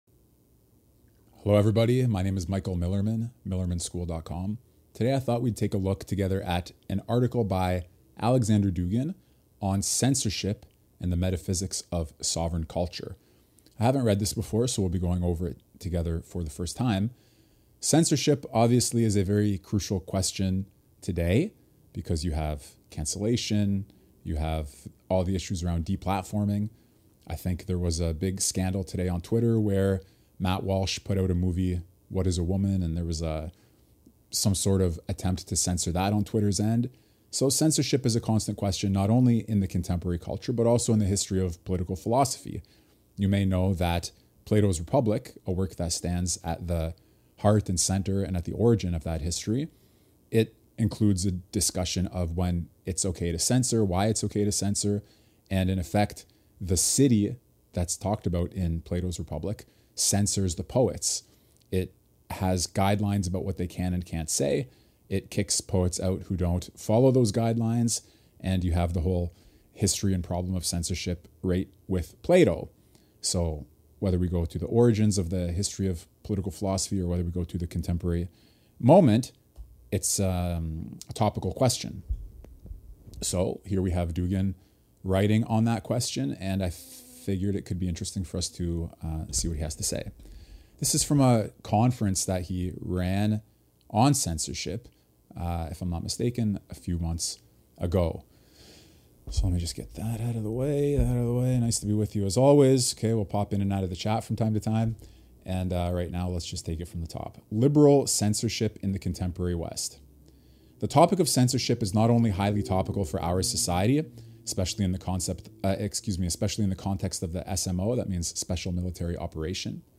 Alexander Dugin on Censorship (Article Reading)
Friday June 2nd Livestream. Dugin on censorship.